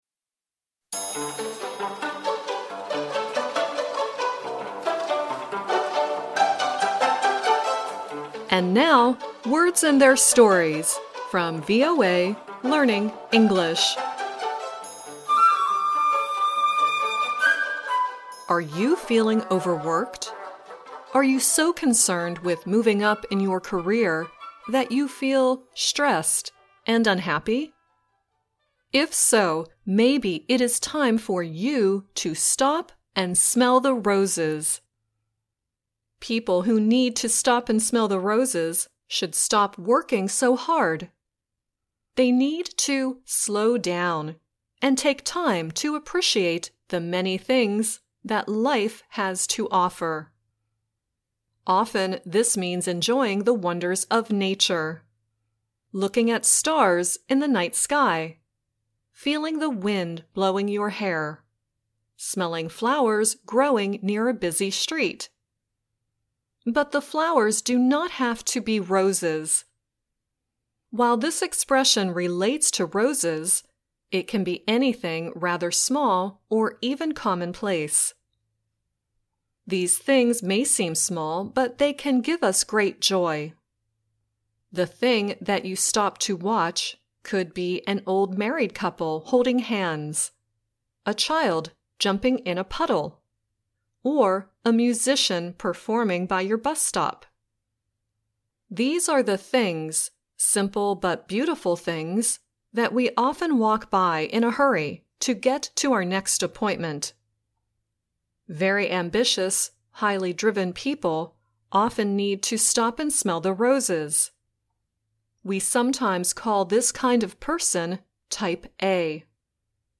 The song at the end is the rock band Poison singing "Every Rose Has Its Thorns."